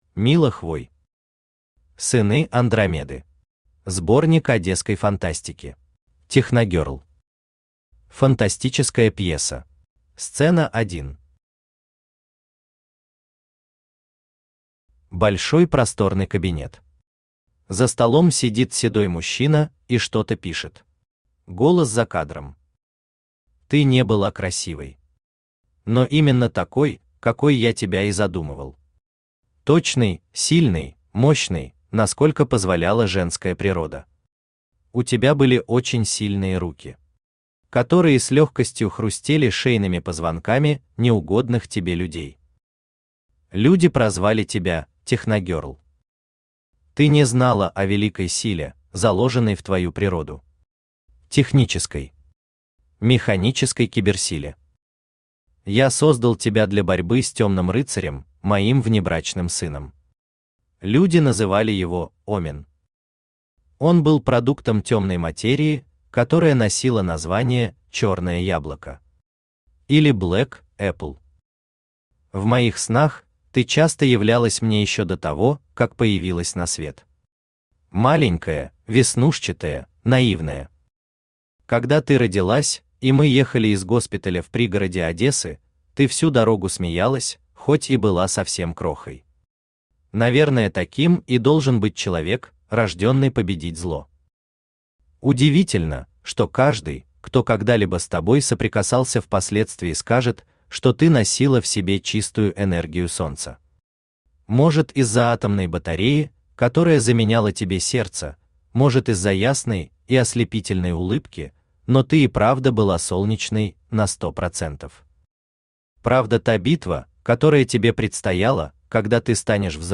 Аудиокнига Сыны Андромеды. Сборник одесской фантастики | Библиотека аудиокниг
Читает аудиокнигу Авточтец ЛитРес.